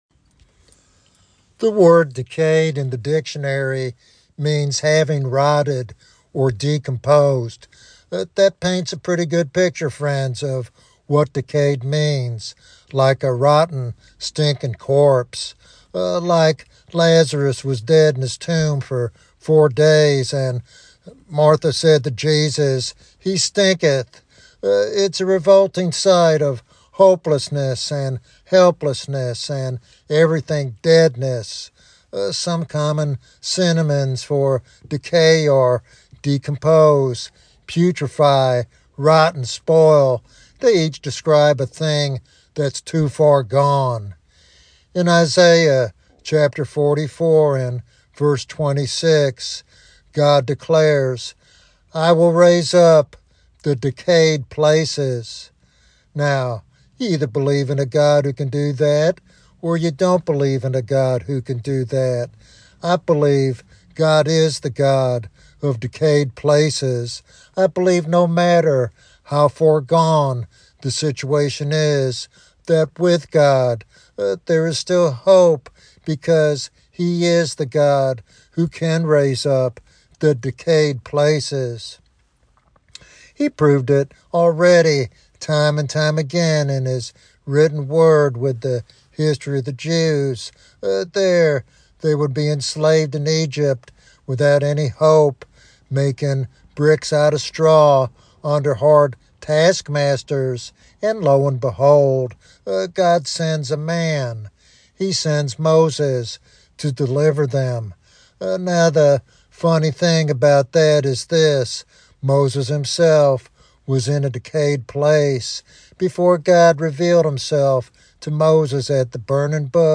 This sermon encourages believers to trust in God's promise found in Isaiah 44:26 and to hold onto hope regardless of their circumstances.